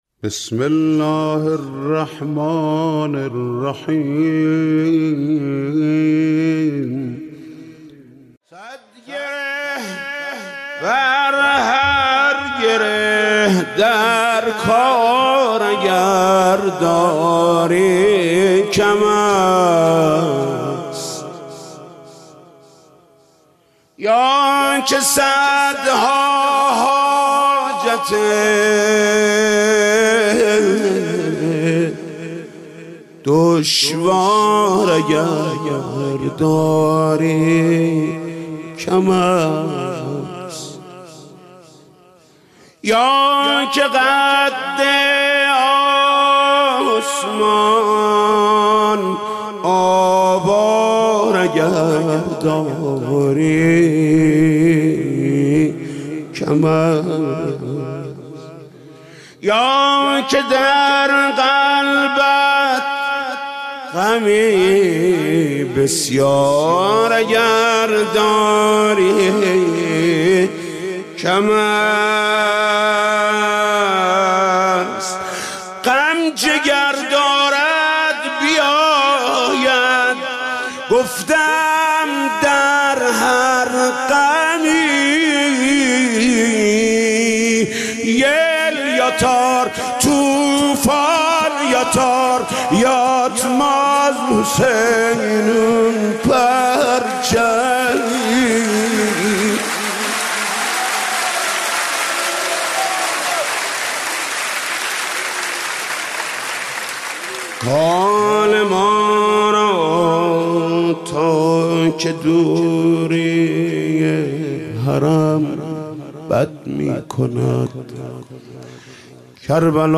مولودی ومدیحه خوانی بسیارزیبای ولادت حضرت عباس(ع)، حاج محمودکریمی -(صدگره بر هر گره درکار اگر داری کم است...)